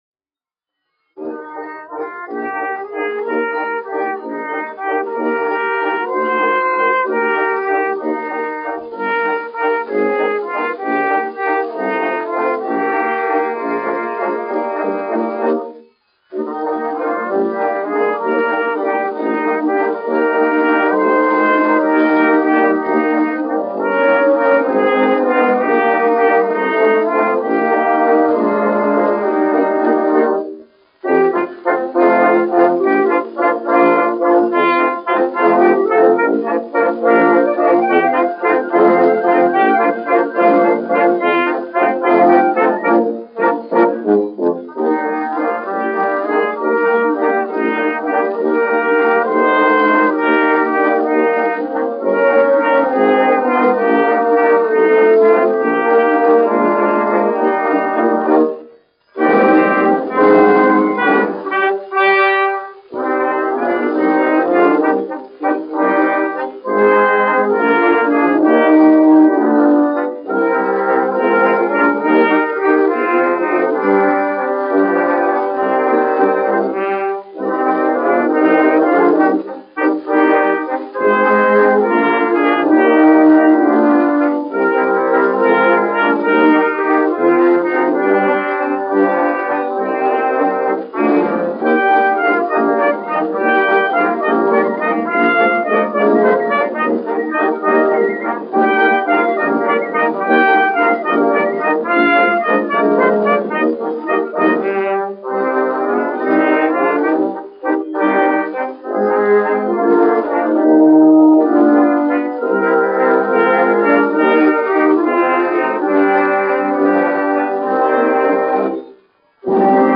1 skpl. : analogs, 78 apgr/min, mono ; 25 cm
Valši
Pūtēju orķestra mūzika
Latvijas vēsturiskie šellaka skaņuplašu ieraksti (Kolekcija)